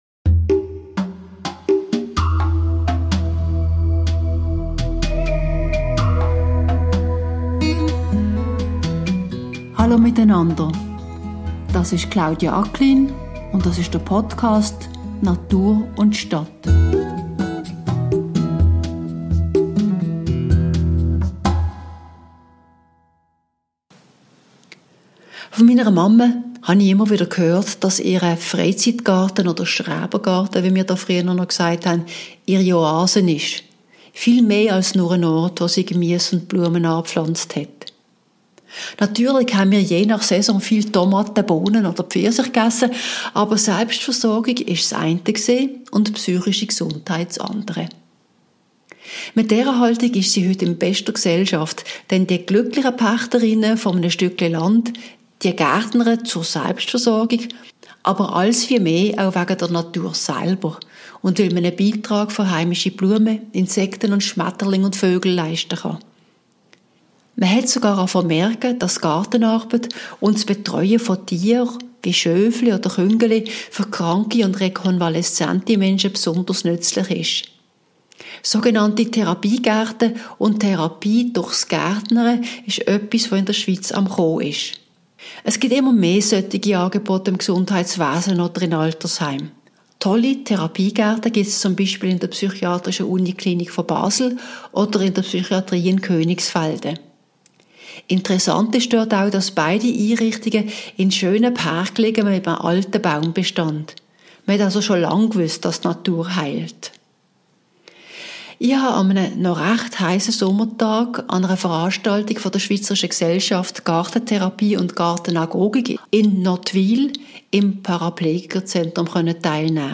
Zu Gast waren wir im Therapiegarten des Paraplegiker-Zentrums in Nottwil, wo wir durch eine Ergotherapeutin begrüsst wurden.